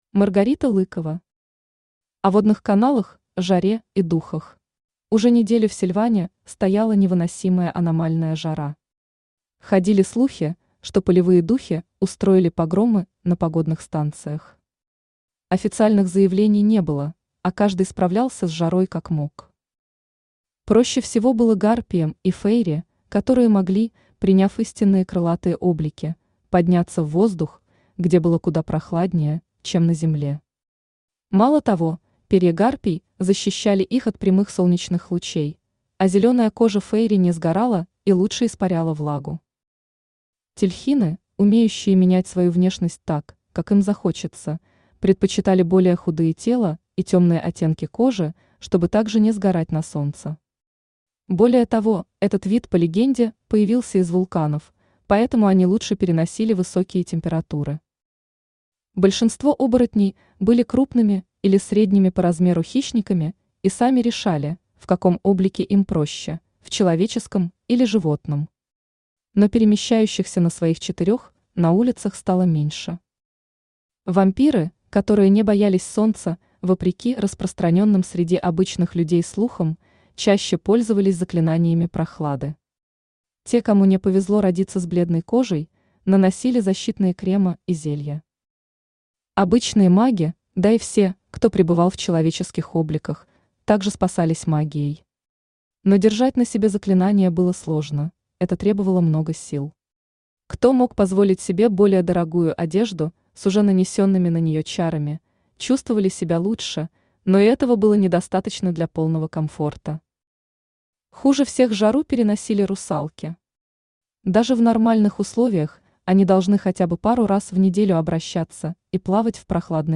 Аудиокнига О водных каналах, жаре и духах | Библиотека аудиокниг